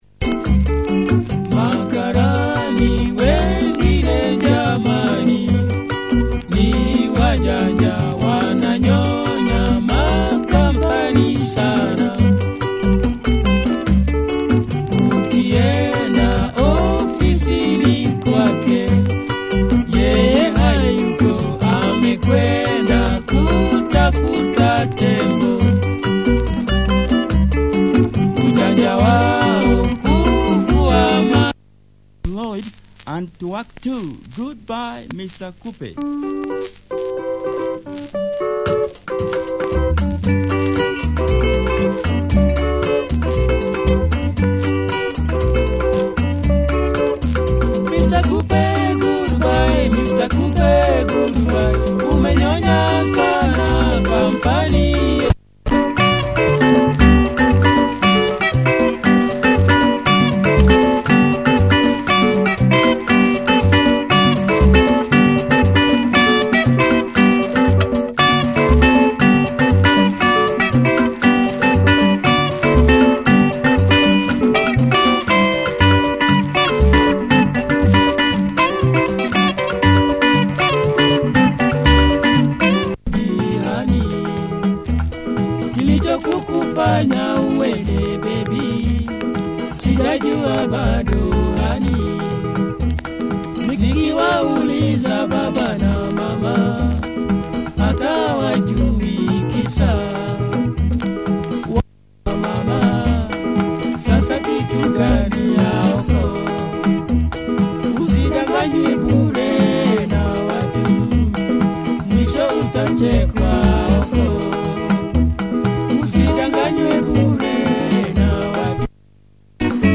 really good guitar